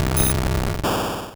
Cri de Spectrum dans Pokémon Rouge et Bleu.